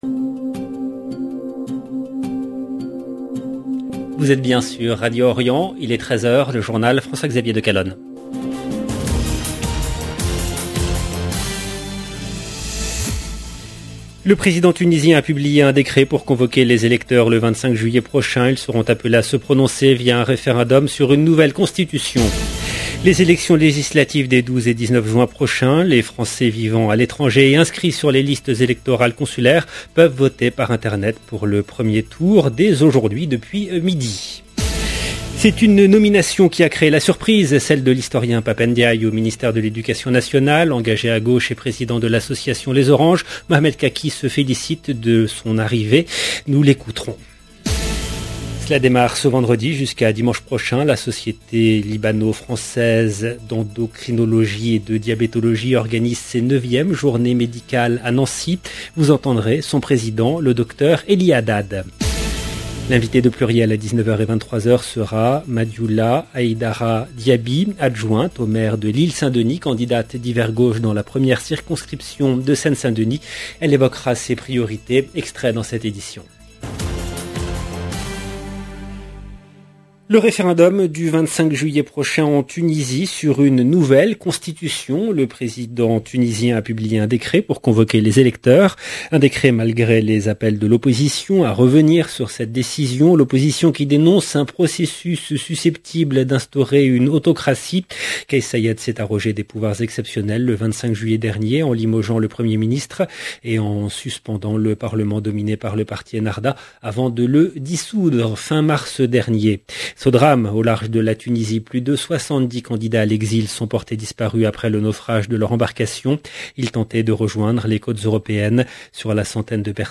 LE JOURNAL DE 13 H EN LANGUE FRANCAISE DU 27/5/2022